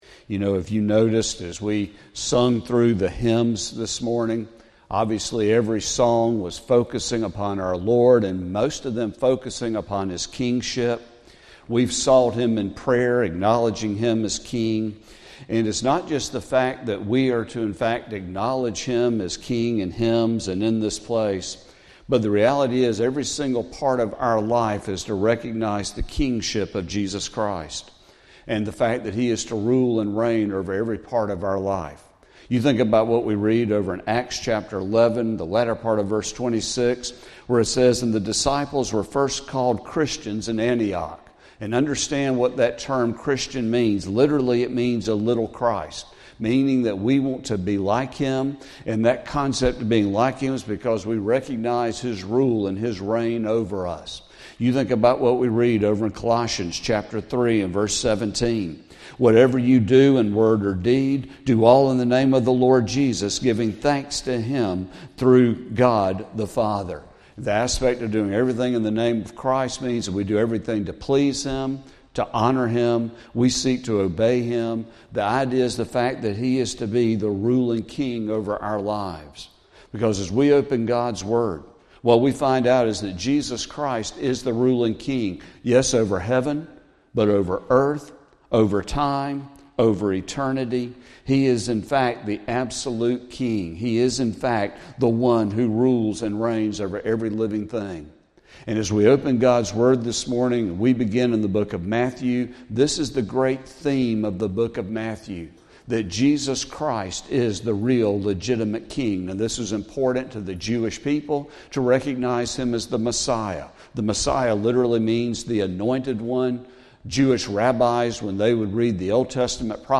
Sermon | March 30, 2025